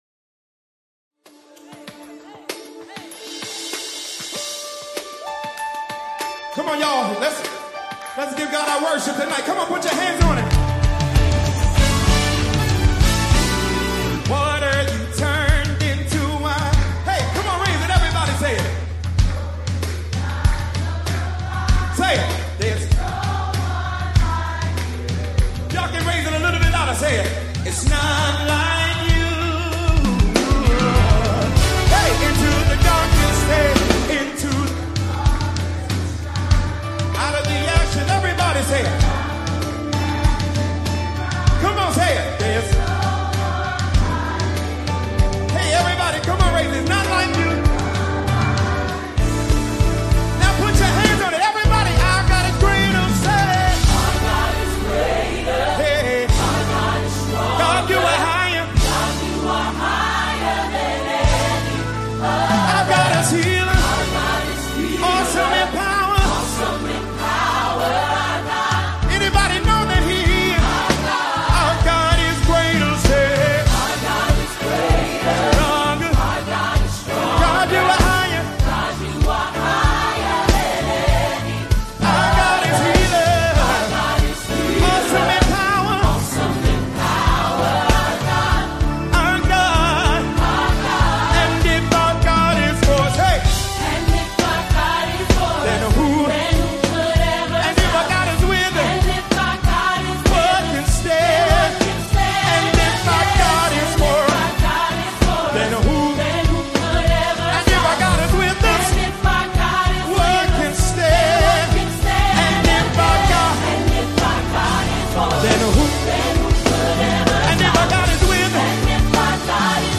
January 24, 2025 Publisher 01 Gospel 0